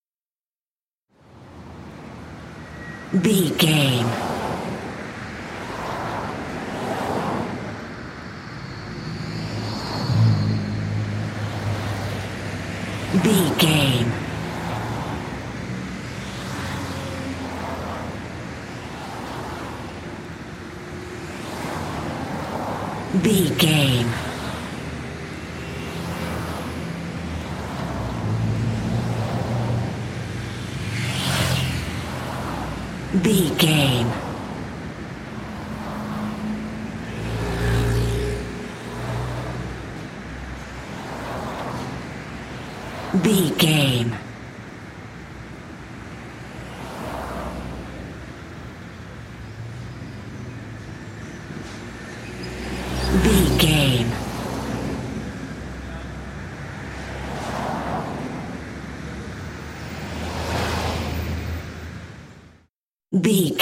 Traffic cars passby
Sound Effects
urban
ambience